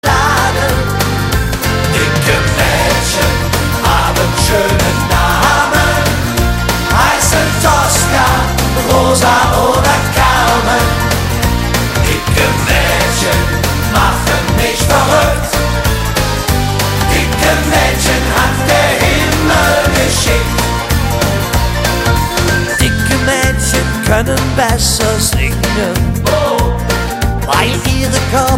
Gattung: Moderner Einzeltitel
A-B Besetzung: Blasorchester PDF